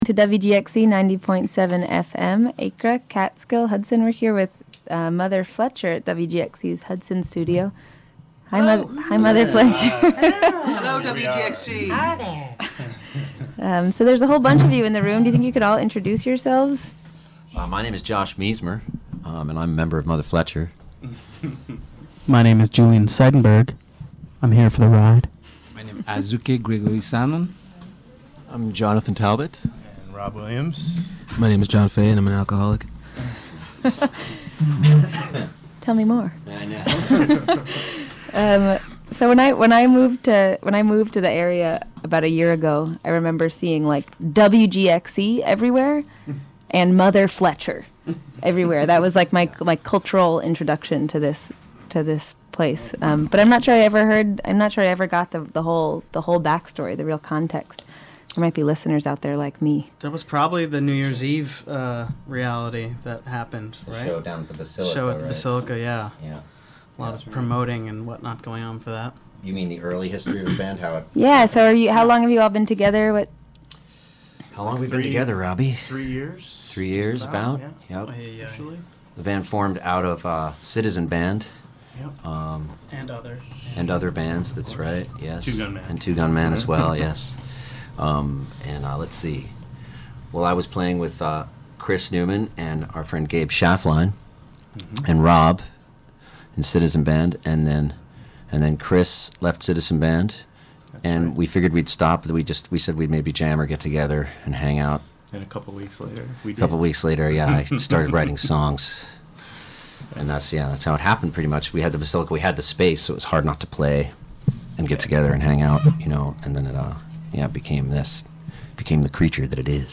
Mother Fletcher interview on WGXC Afternoon Show. (Audio)
Interview came after in-studio performance, and was during WGXC's first Pledge Drive.